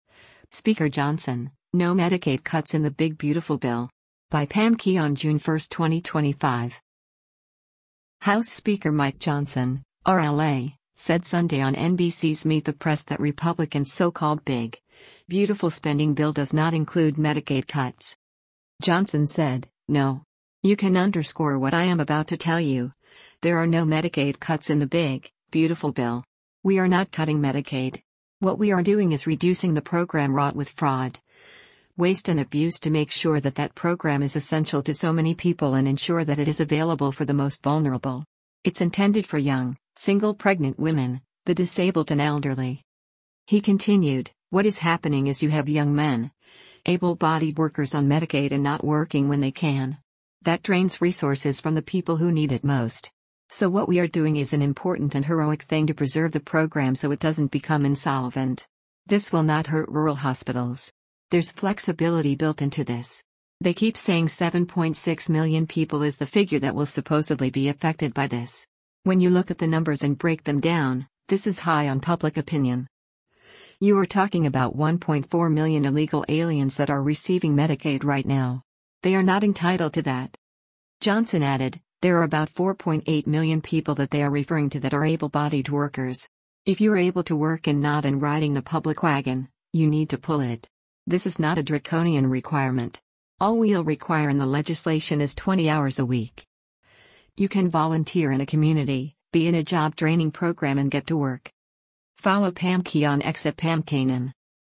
House Speaker Mike Johnson (R-LA) said Sunday on NBC’s “Meet the Press” that Republicans’ so-called “big, beautiful” spending bill does not include Medicaid cuts.